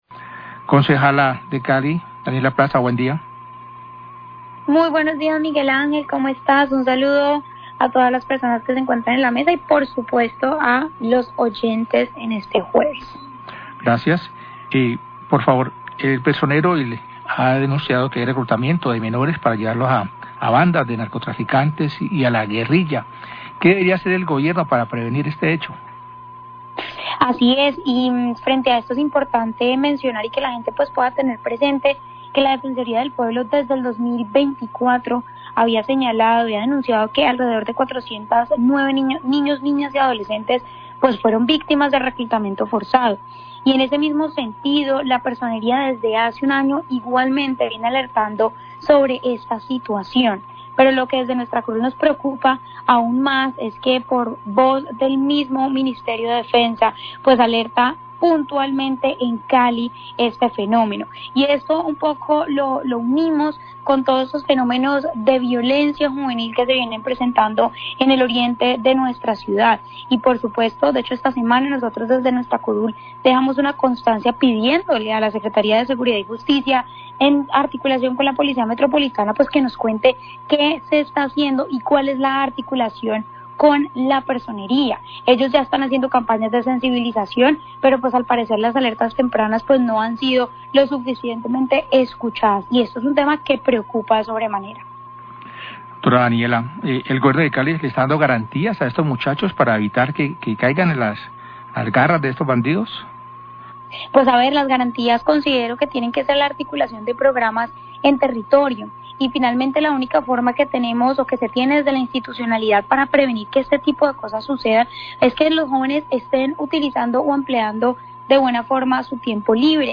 NOTICIERO RELÁMPAGO
Concejal Daniella Plaza habla acerca de las alertas por reclutamiento de menores de edad en Cali.